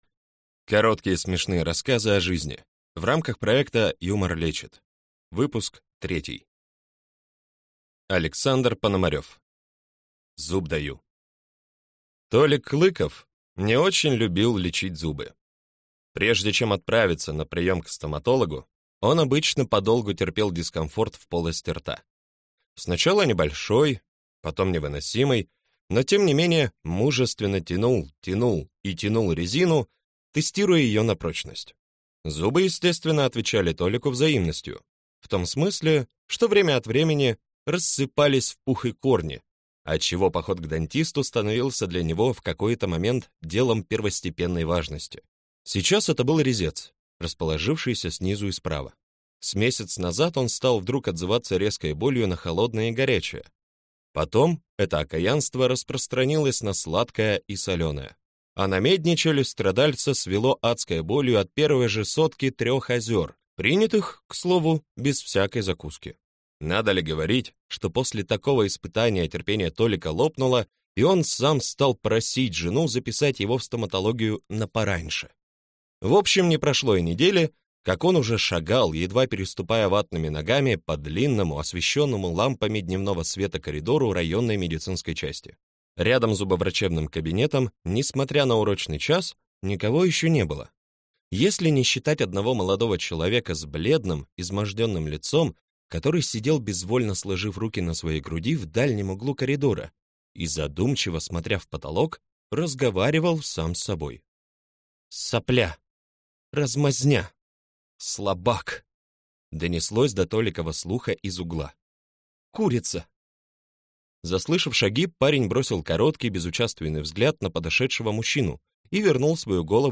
Аудиокнига Короткие смешные рассказы о жизни 3 | Библиотека аудиокниг